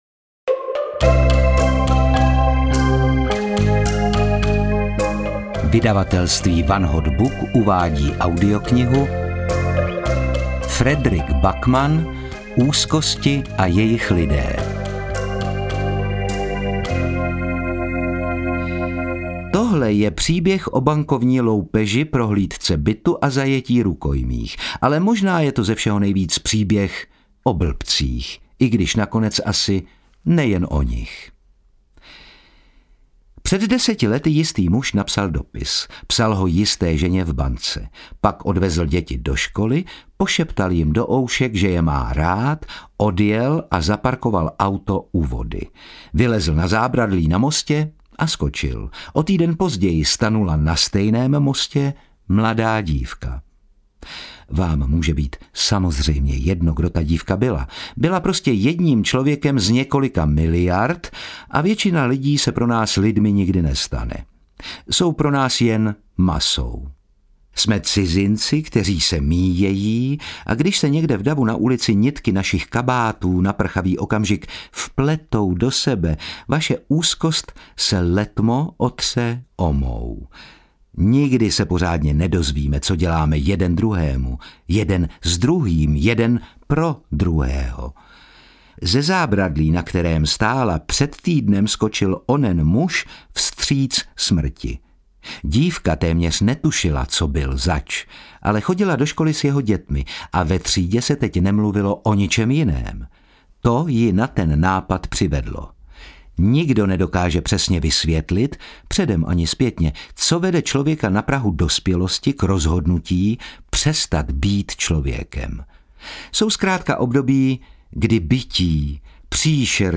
Úzkosti a jejich lidé audiokniha
Ukázka z knihy
• InterpretOtakar Brousek ml.